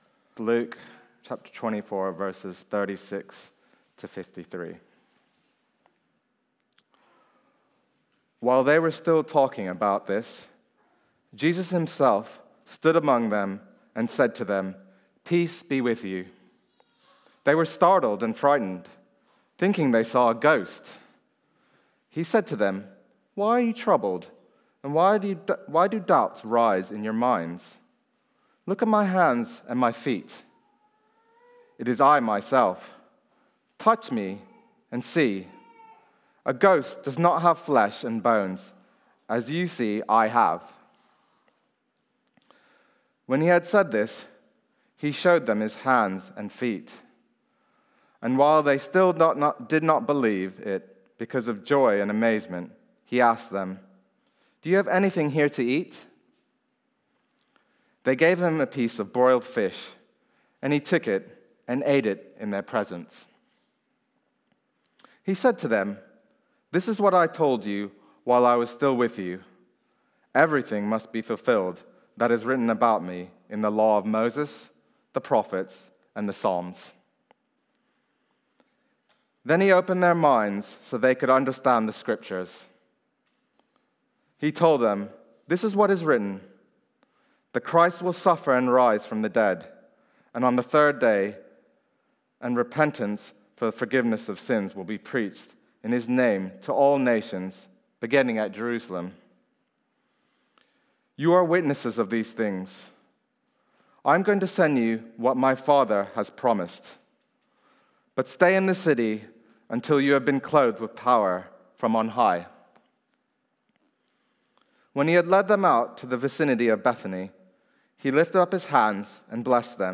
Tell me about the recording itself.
Media for Sunday Service on Sun 30th Apr 2023 10:00